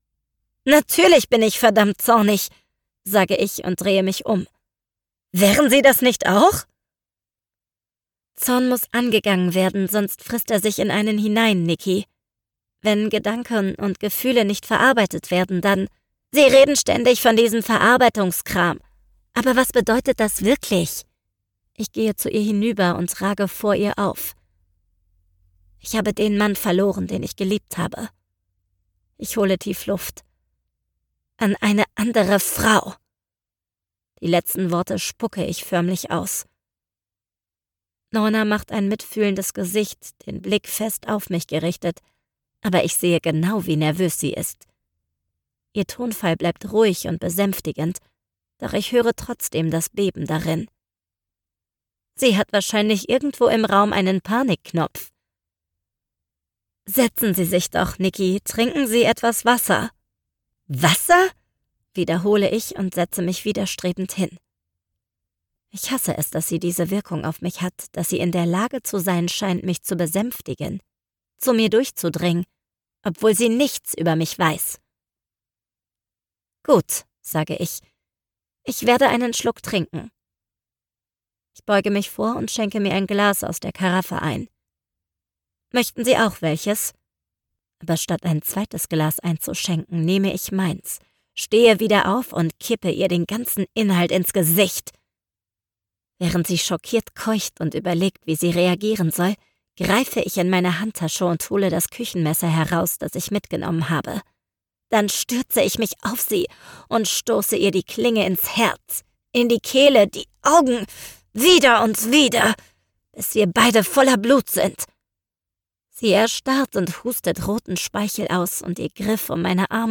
Sprecherin. Professional Voice Artist.
Meine Stimme hat eine moderne, etwas rauchige, aber dennoch frische Klangfarbe mit hohem Wiedererkennungseffekt.
Mein Stimmfarbe ist jung, trendig, warm, seriös und angenehm.